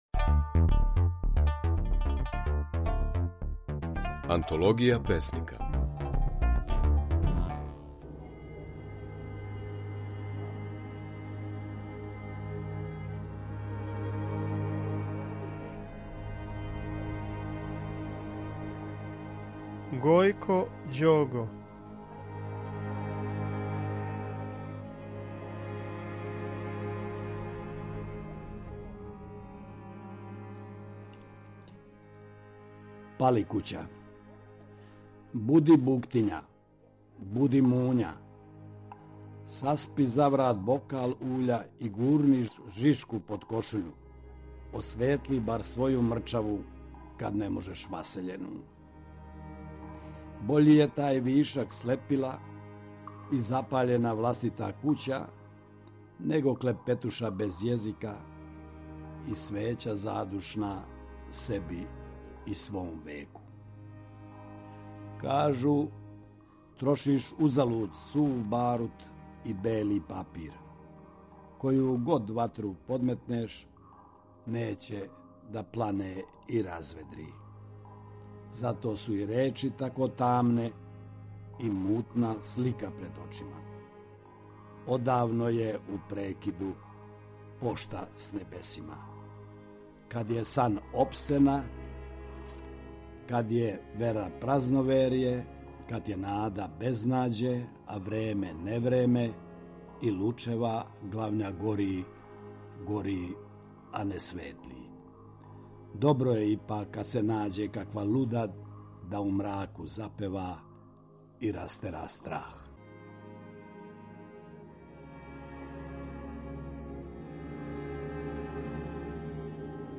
У емисији Антологија песника своје стихове говори песник Гојко Ђого (1940, Влаховићи, Љубиње).